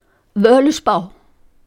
Listen to pronunciation: völuspá